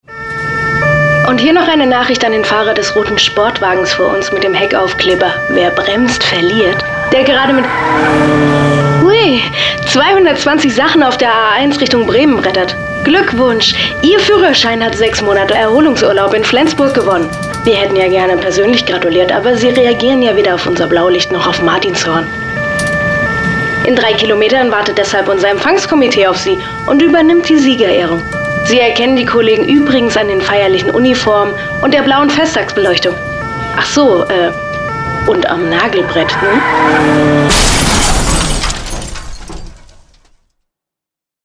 Commercial – „Raser“